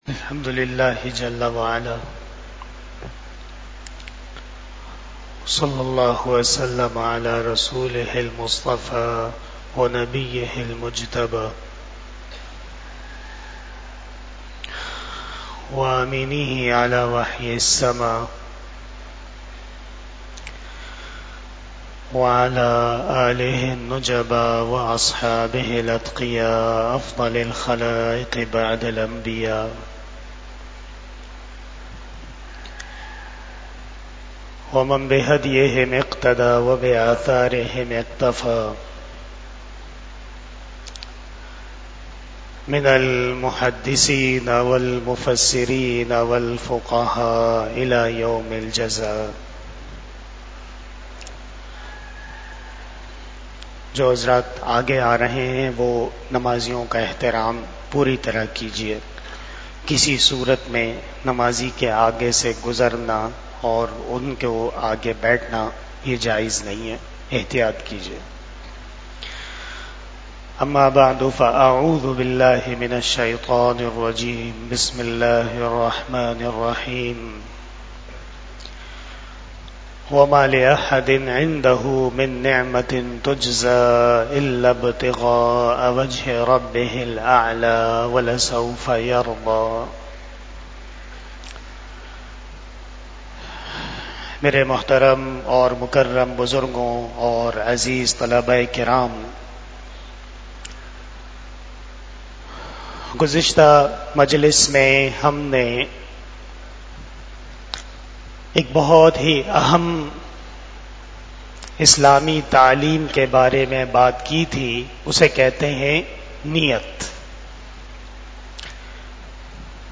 Islahi Bayan After Maghrib Bayan 01 September 2024 (27 Safar 1446 HJ) Sunday
بیان اصلاحی مجلس ۲۷صفر المظفر ۱٤٤٦ھ بمطابق یکم ستمبر 2024ء